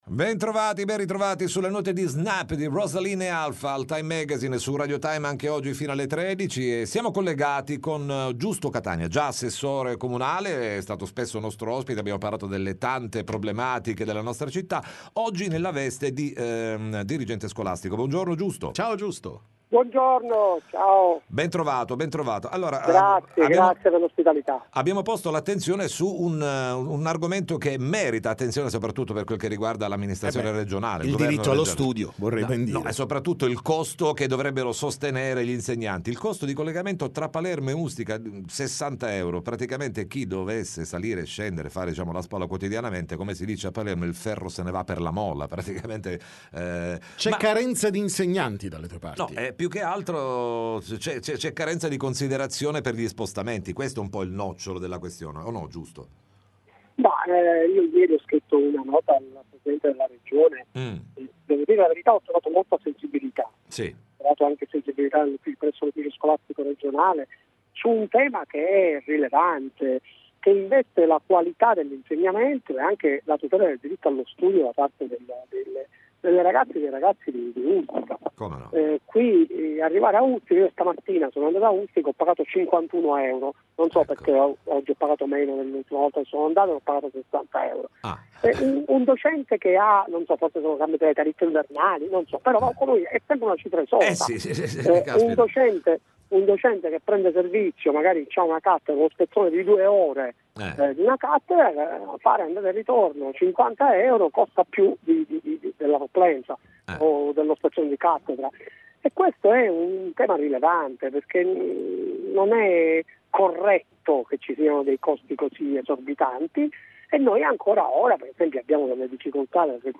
TM Intervista Giusto Catania